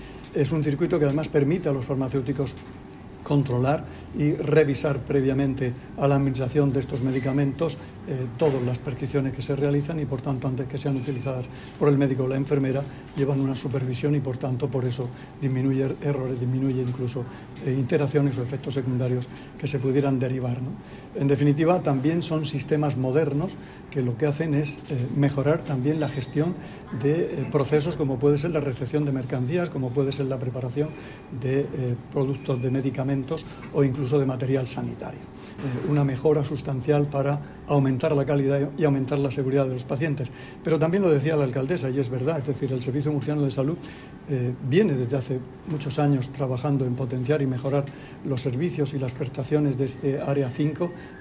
Declaraciones del consejero de Salud, Juan José Pedreño, sobre el Servicio de Farmacia Hospitalaria del hospital de Yecla